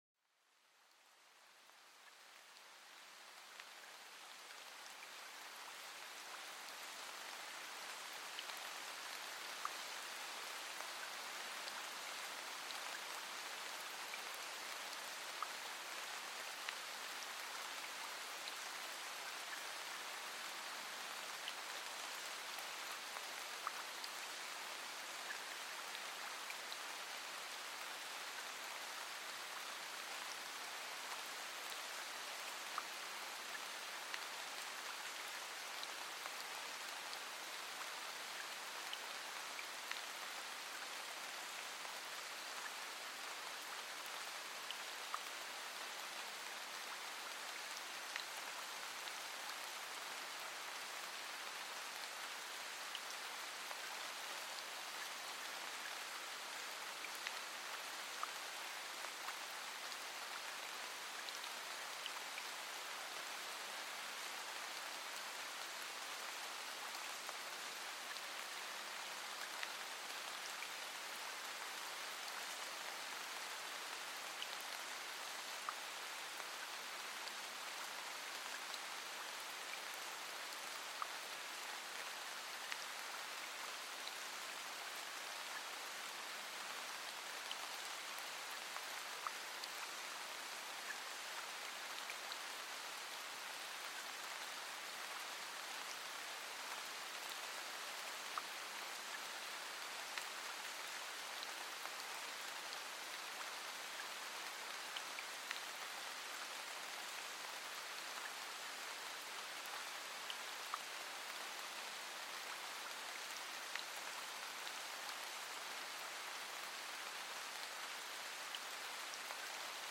Plongez dans la sérénité d'une douce pluie avec cet épisode apaisant. Écoutez le son des gouttes de pluie qui caressent délicatement les feuilles, créant une atmosphère de tranquillité parfaite pour la relaxation.